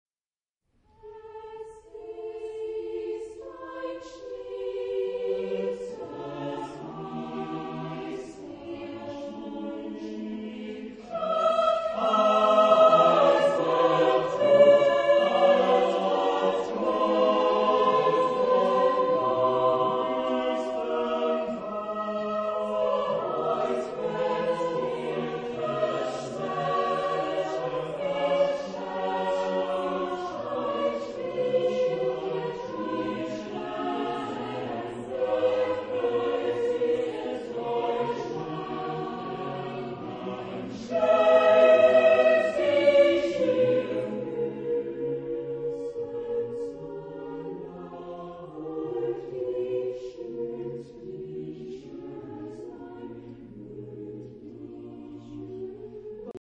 Género/Estilo/Forma: Folklore ; Madrigal ; Profano
Tipo de formación coral: SATB  (4 voces Coro mixto )
Tonalidad : dorico
Ref. discográfica: 7. Deutscher Chorwettbewerb 2006 Kiel